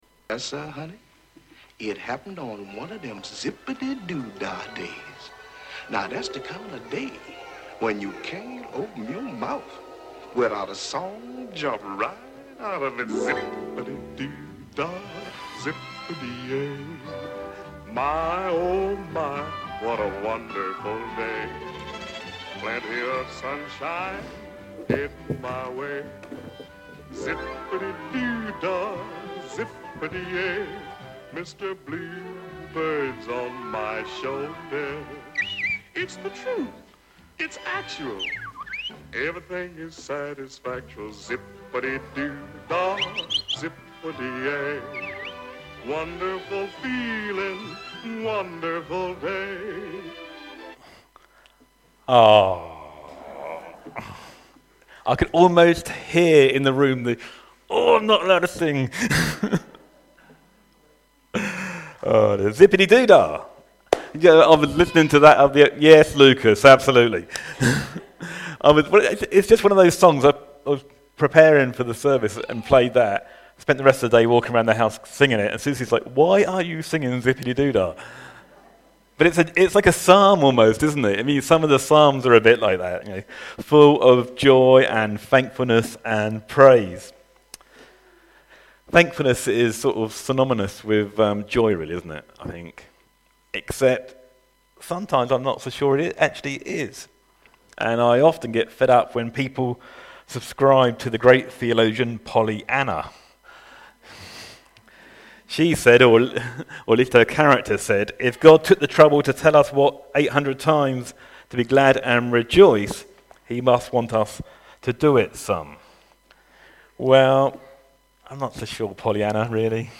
Sermon July 4th, 2021 - Thank You Day - Bedhampton Church